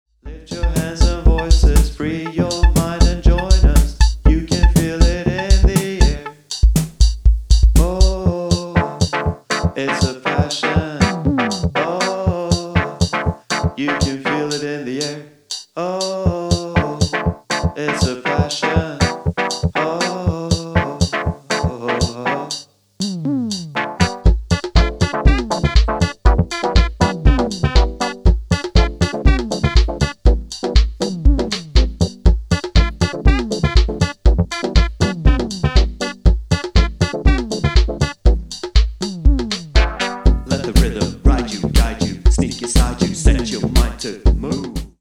Genre Electro